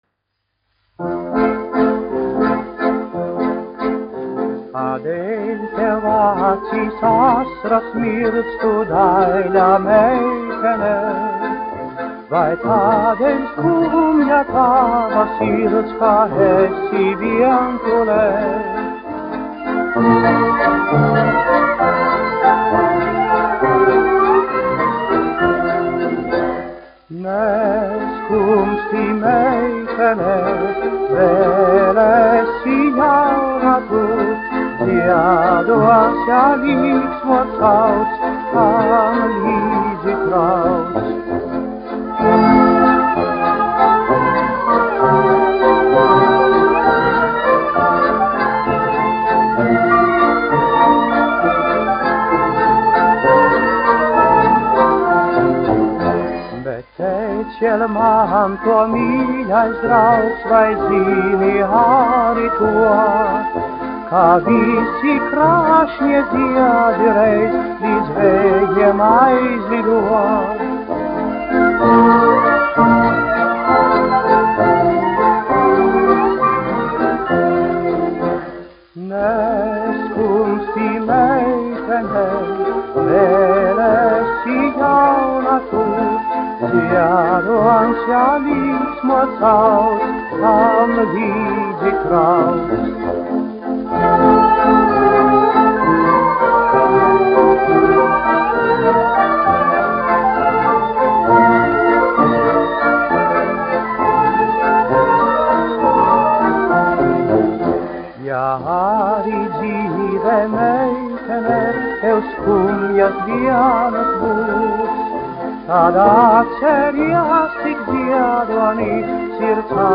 1 skpl. : analogs, 78 apgr/min, mono ; 25 cm
Populārā mūzika
Skaņuplate